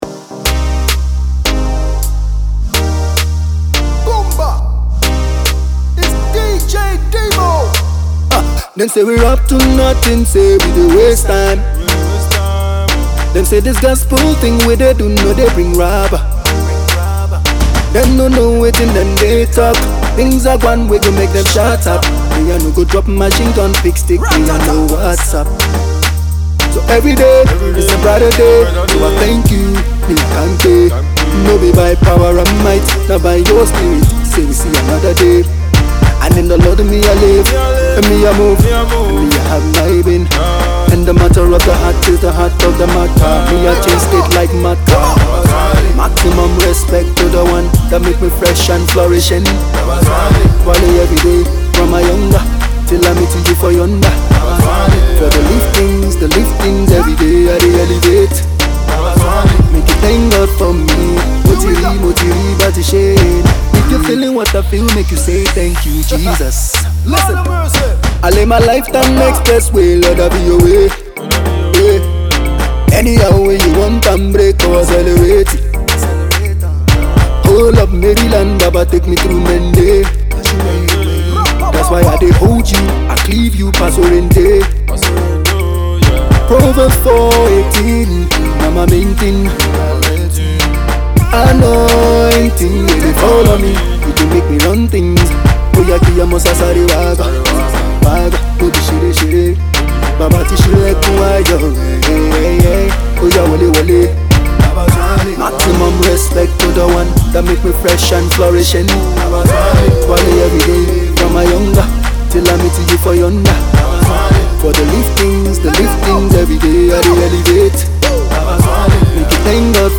a song of praise and thanksgiving to God